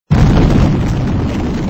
b_baozha2.mp3